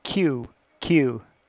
Transcription Practice:  English Dialects and Allophones